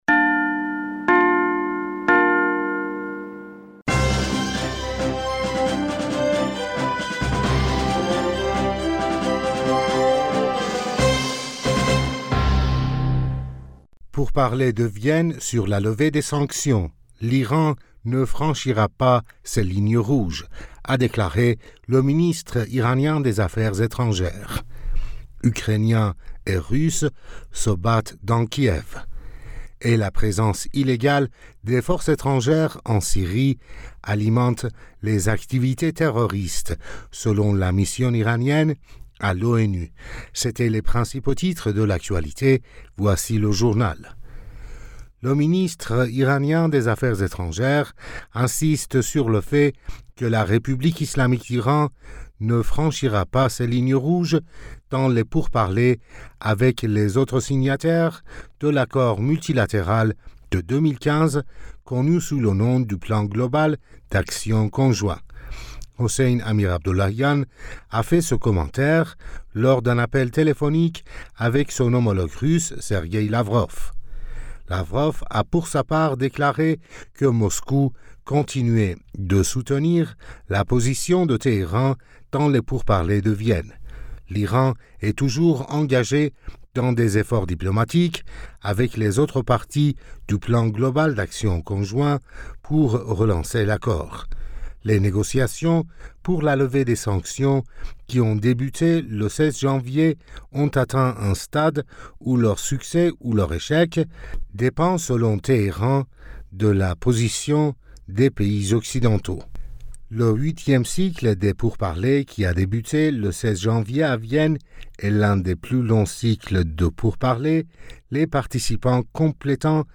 Bulletin d'information Du 26 Fevrier 2022